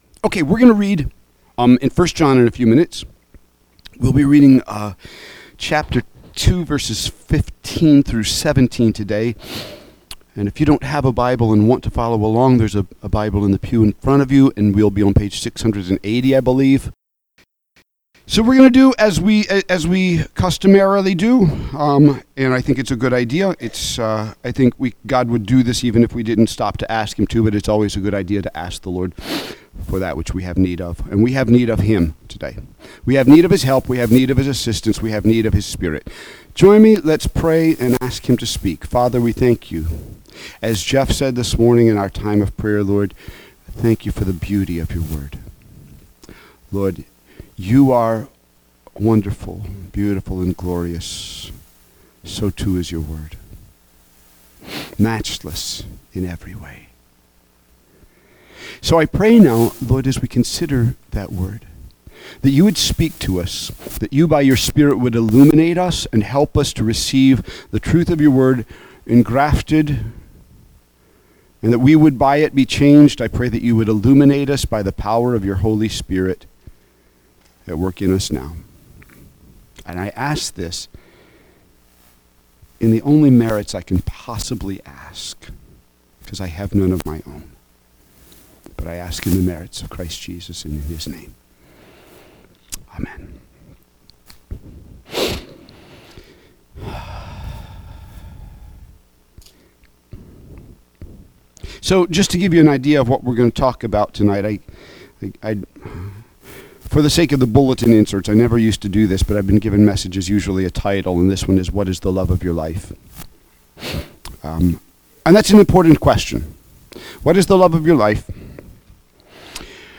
An archive of mp3s of our messages and sermons given at Abundant Life Fellowship Christian church